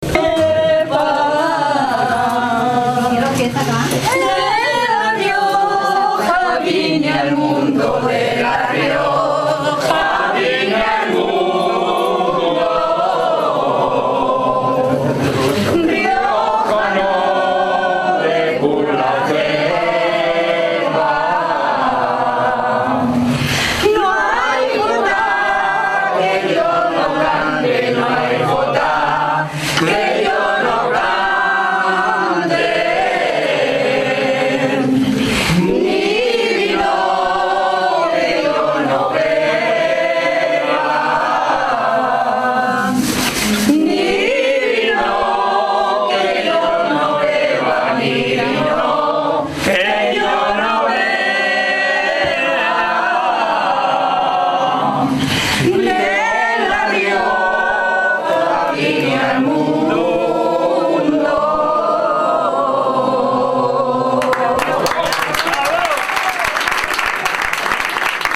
Hubo luego una interesante experiencia enológica, con visita a la Sala de Sacacorchos del Museo Vivanco de Briones y a la cava del restaurante ‘La Vieja Bodega de Casalarreina’, donde se tomó un tentempié y se realizó una breve lectura en braille, además de animarse el grupo de afiliados con
una jota riojana formato MP3 audio(1,30 MB) en toda la regla.